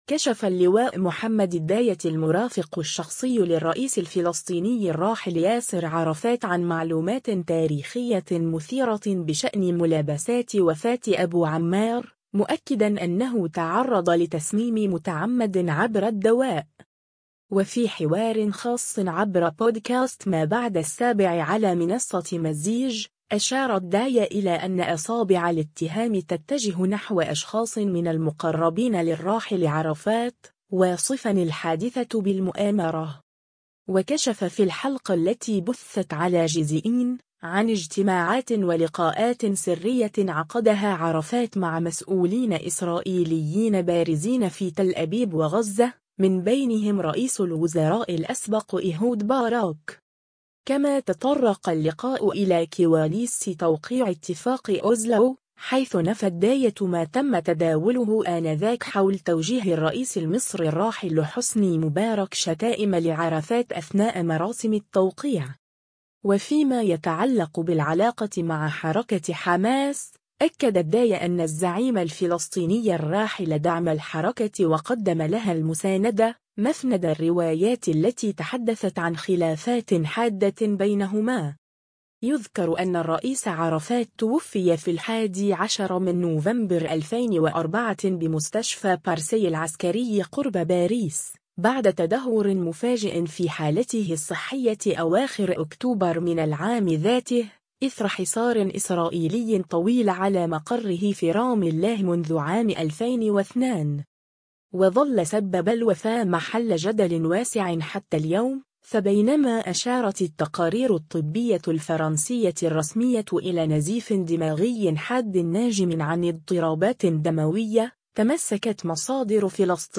حوار خاص